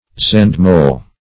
Search Result for " zandmole" : The Collaborative International Dictionary of English v.0.48: Zandmole \Zand"mole`\, n. [Cf. D. zand sand.